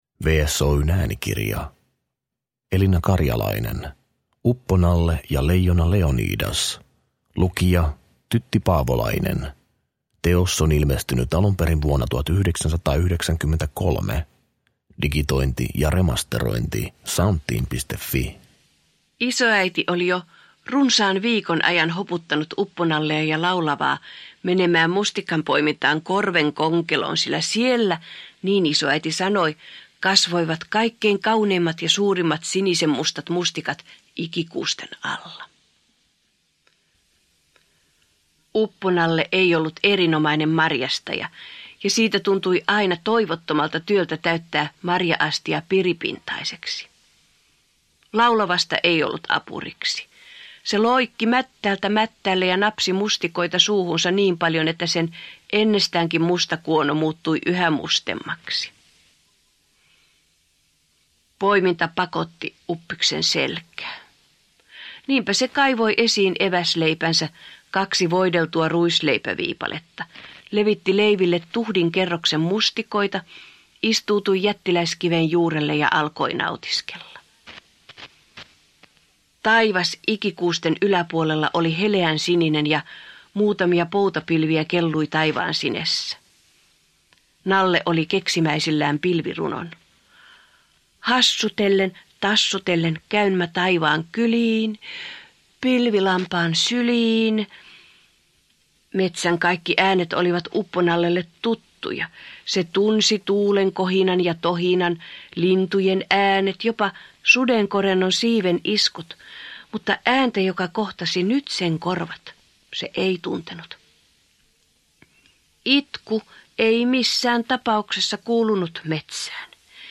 Uppo-Nalle ja leijona Leonidas – Ljudbok – Laddas ner